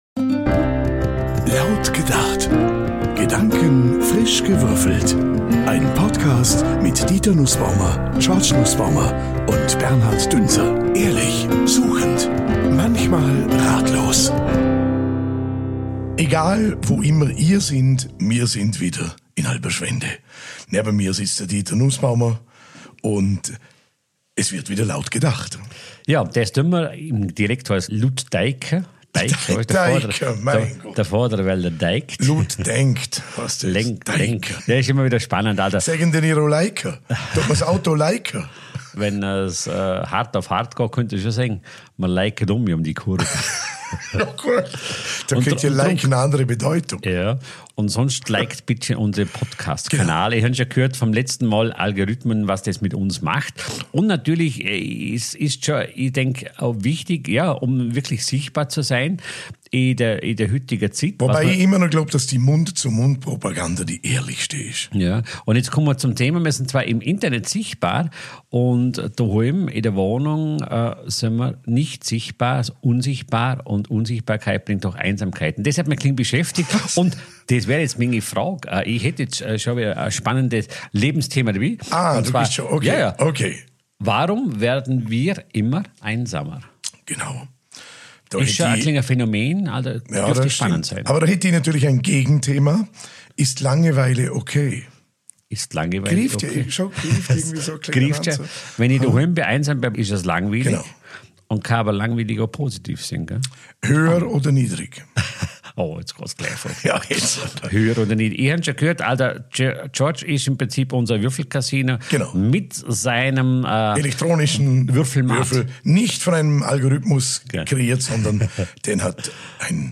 Dieses Mal stehen zur Auswahl: Ist langeweile OK? Oder: Wieso werden wir immer einsamer? Was folgt, ist ein ehrliches, spontanes Gespräch: mal tiefgründig, mal leicht, mal überraschend anders.
Laut gedacht ist ein Podcast ohne Drehbuch, aber mit Haltung.